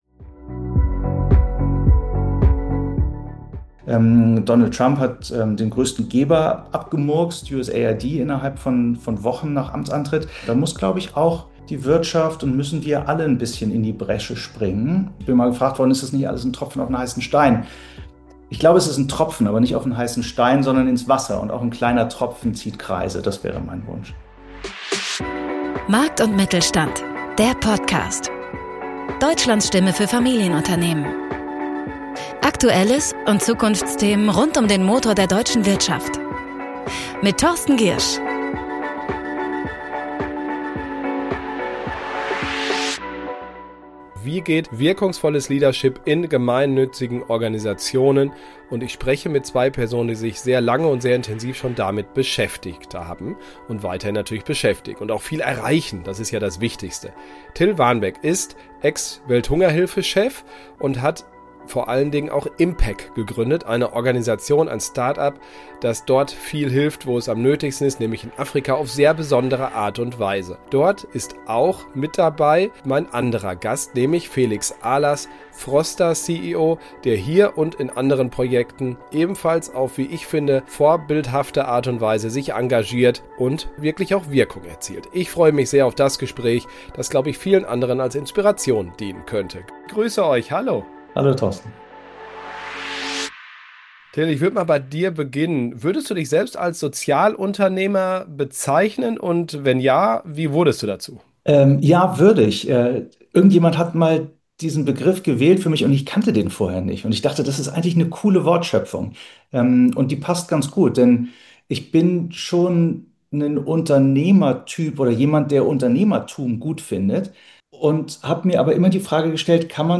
Ein Gespräch über Verantwortung, Unternehmertum und Hilfe, die wirkt.